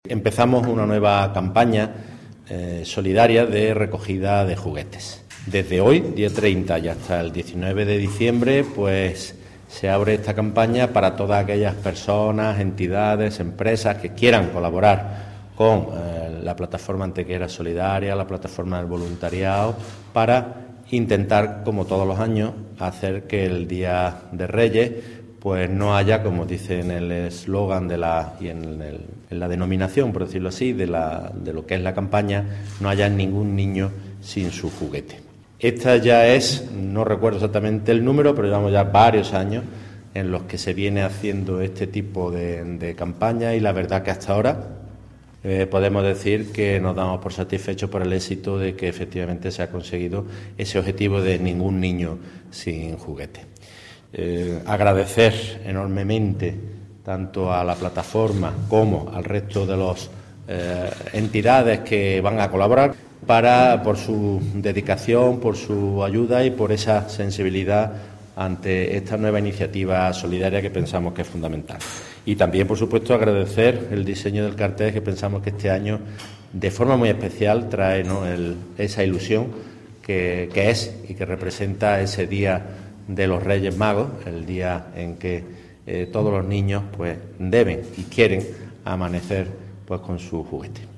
El teniente de alcalde delegado de Familia, Programas Sociales, Educación y Salud, José Luque, y la teniente de alcalde de Igualdad, Equidad, Accesibilidad y Cooperación Ciudadana, María Dolores Gómez, han presentado en la mañana de hoy una nueva edición de la campaña solidaria "Ningún Niño sin Juguete".
Cortes de voz